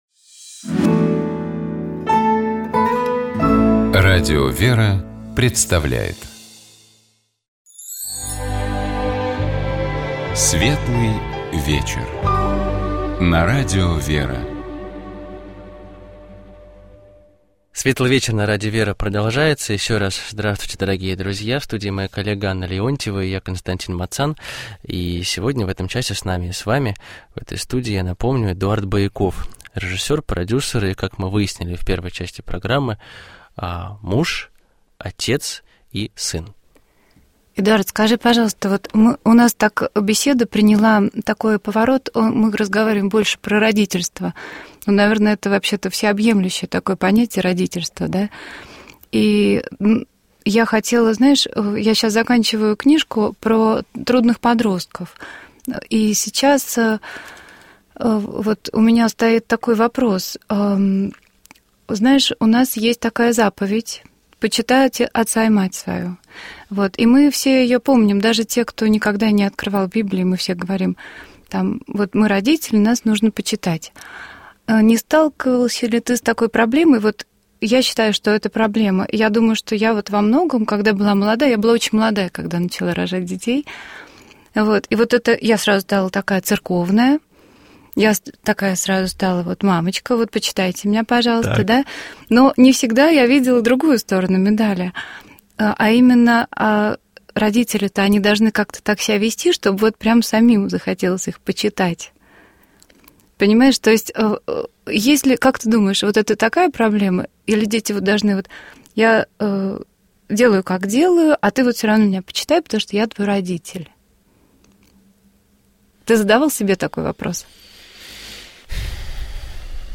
У нас в гостях был продюсер, режиссер Эдуард Бояков.